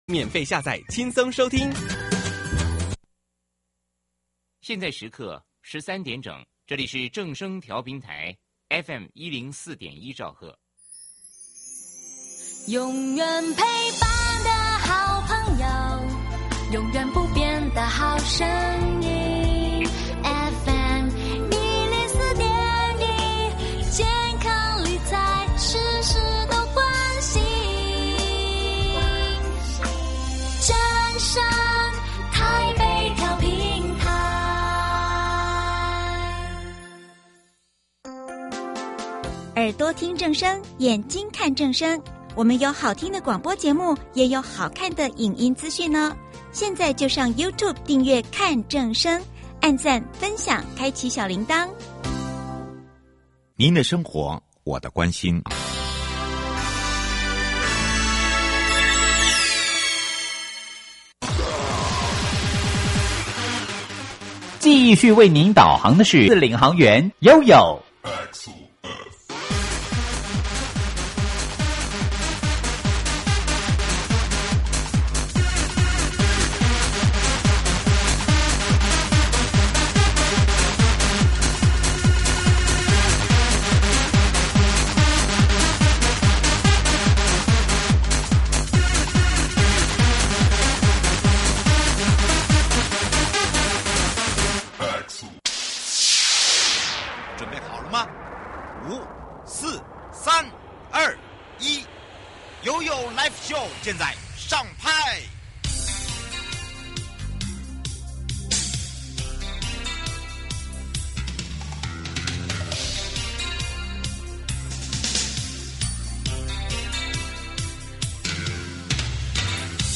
受訪者： 營建你我他 快樂平安行~七嘴八舌講清楚~樂活街道自在同行! 主題：前瞻基礎建設計畫-提升道路品質計畫